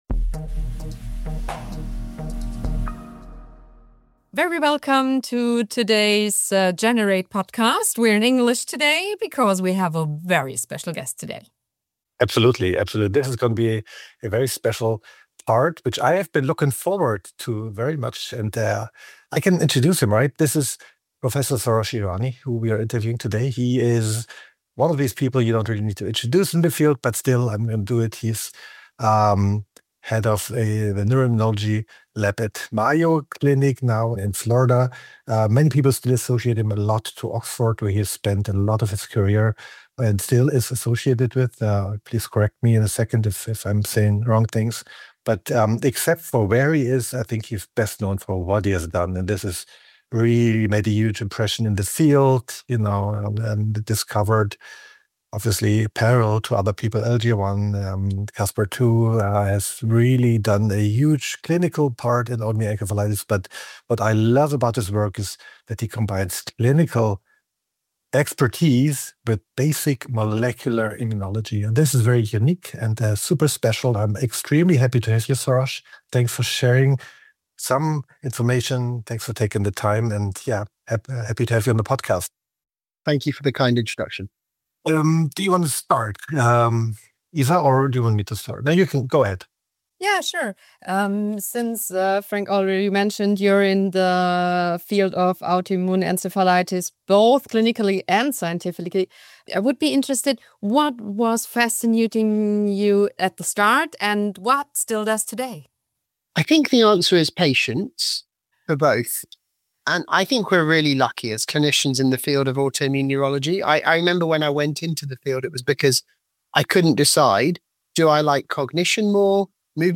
April 2026 download Beschreibung Teilen Abonnieren Klinik und Forschung im Dialog Gast